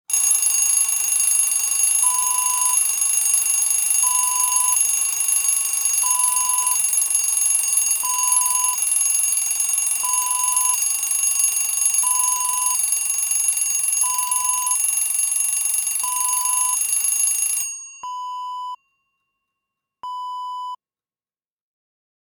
Alarm Clock Ringing Wav Sound Effect #2
Description: An old-style alarm clock ringing
Properties: 48.000 kHz 24-bit Stereo
A beep sound is embedded in the audio preview file but it is not present in the high resolution downloadable wav file.
alarm-clock-preview-3.mp3